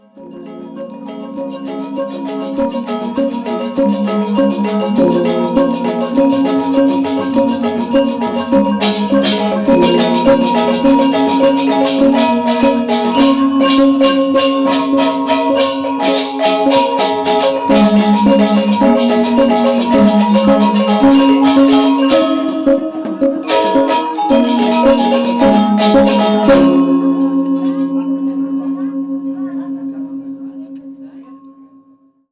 Balinese Gamelan Angklung Cremation Music
An important presence through centuries of musical and social change, gamelan angklung is a small, four-tone bronze-keyed ensemble that remains ubiquitous at cremations in Bali.
Recording 7.11. Long syncopated link from "Sekar Jepun," pengawak